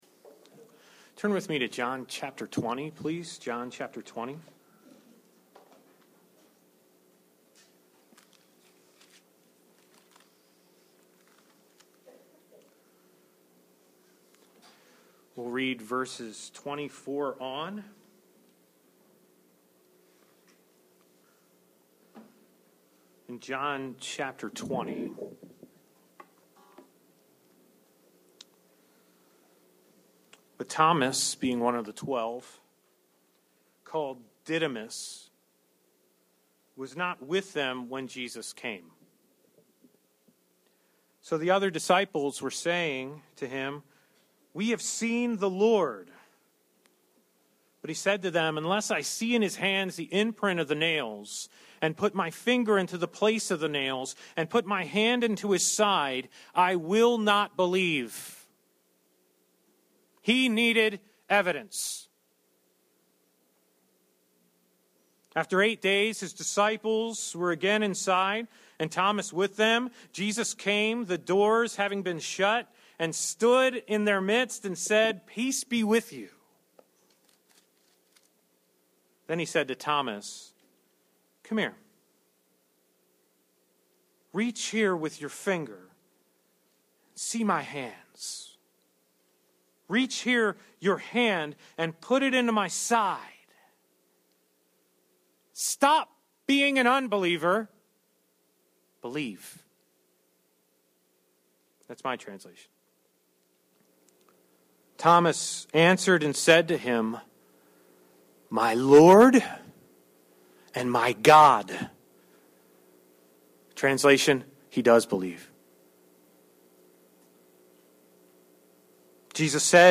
Life In His Name: An expository study of the Gospel according to John Section 1: Words With Friends Sermon 1: What are you trying to prove? John 1:1-5